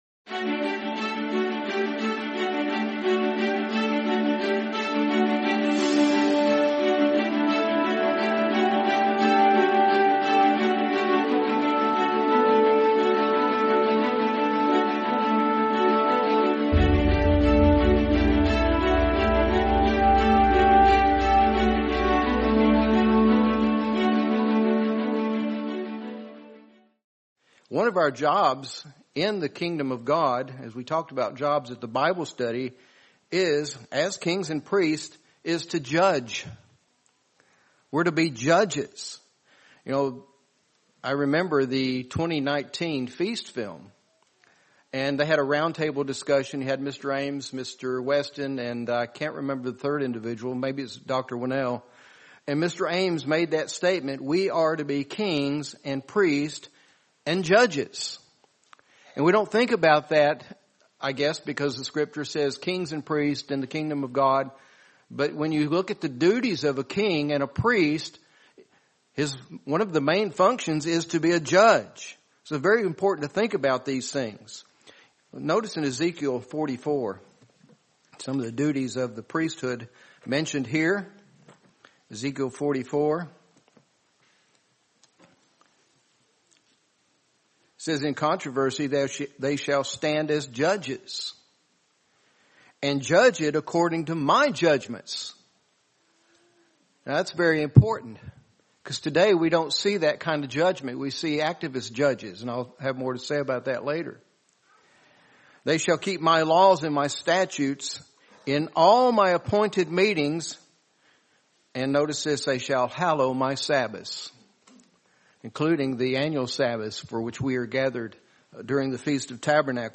Feast of Tabernacles 2025: When Judgment Is Given to Them | Sermon | LCG Members